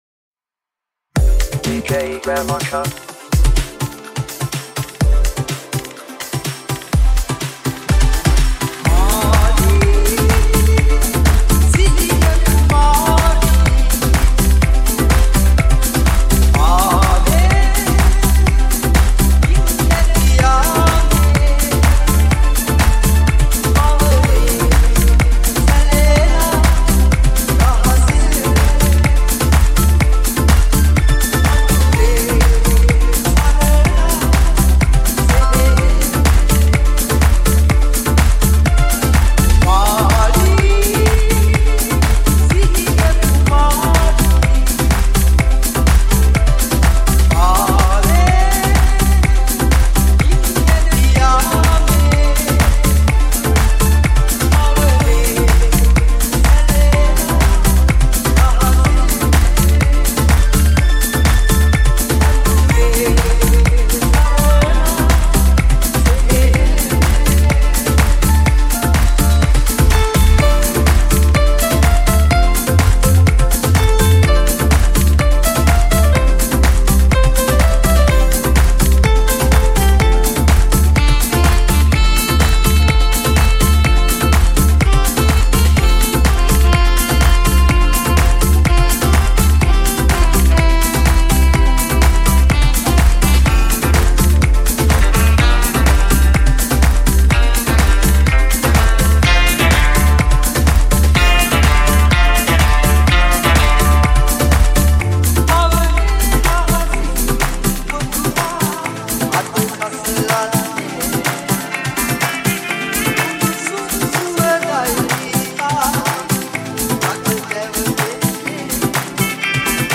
Electro House Remake